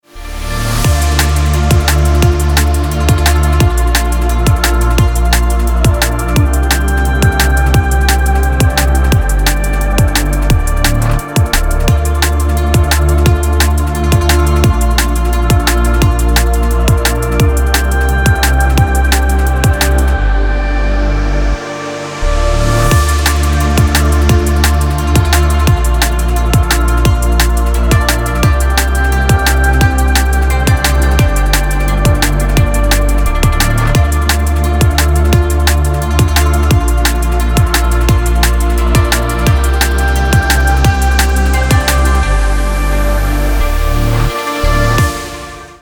DnB рингтоны